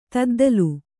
♪ taddalu